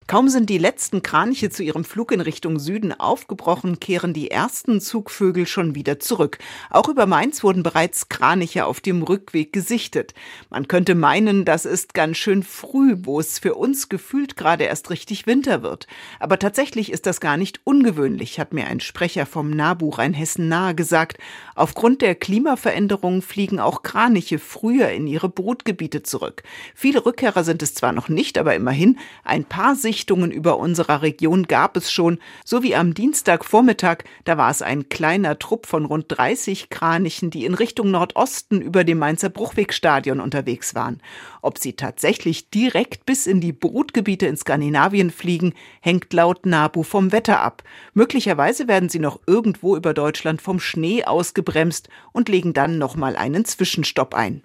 Rund 30 Kraniche über dem Mainzer Bruchwegstadion
Es war nur ein kleiner Zug von rund 30 Vögeln, der am Dienstagvormittag in Richtung Nordosten über das Mainzer Bruchwegstadion zog. Aber die Kraniche waren klar an ihrer typischen V-Formation und ihren charakteristischen Trompetenrufen zu erkennen.